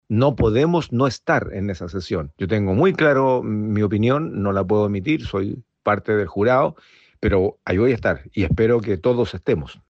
El senado, Iván Flores, fue otro de los que se sumó al llamado a sus colegas: “No podemos no estar en la acusación”, dijo.